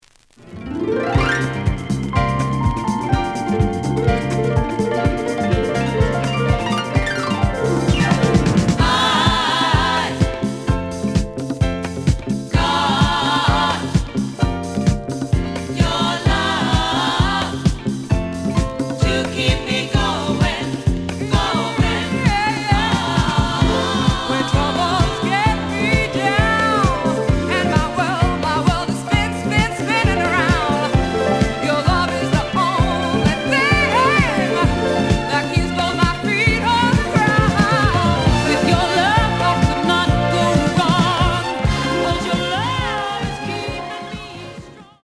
The audio sample is recorded from the actual item.
●Genre: Disco
Slight edge warp. But doesn't affect playing. Plays good.